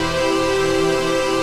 CHRDPAD100-LR.wav